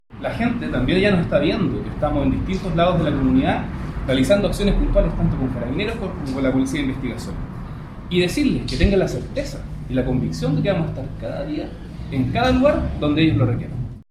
02-DELEGADO-PROVINCIAL-Acciones-Puntuales.mp3